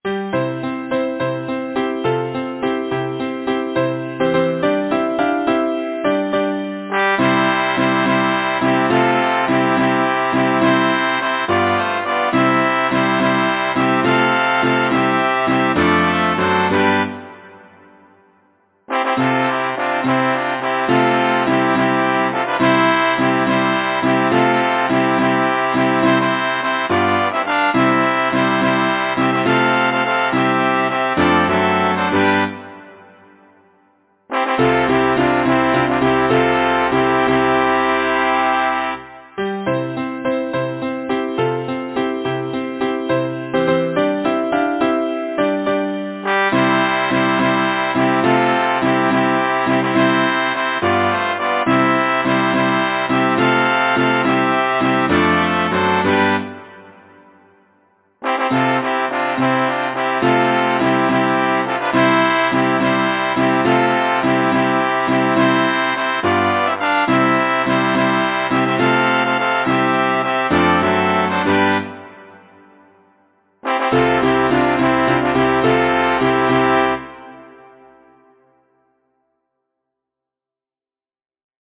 Title: The young May moon Composer: Anonymous (Traditional) Arranger: Michael William Balfe Lyricist: Thomas Moore Number of voices: 4vv Voicing: SATB Genre: Secular, Partsong, Folksong
Language: English Instruments: Piano